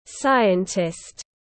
Scientist /ˈsaɪəntɪst/